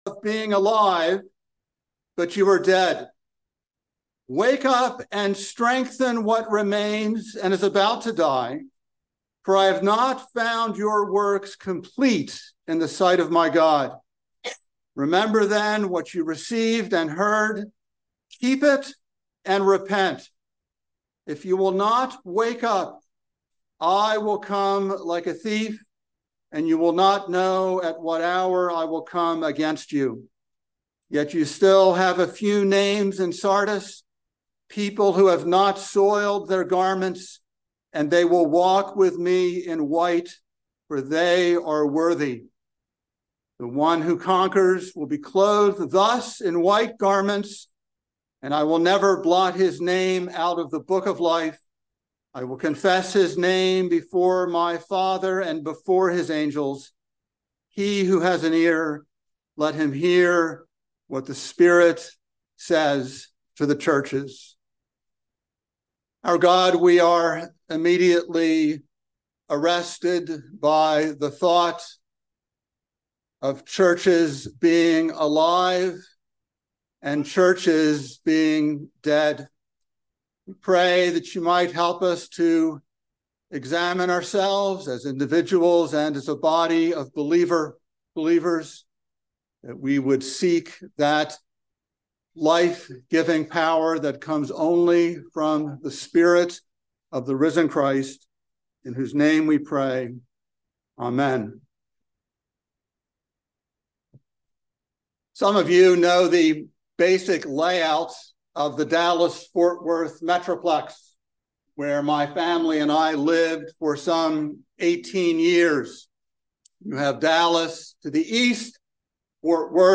by Trinity Presbyterian Church | Oct 10, 2023 | Sermon